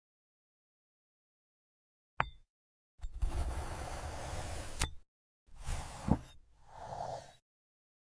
glass cherry ASMR sound effects free download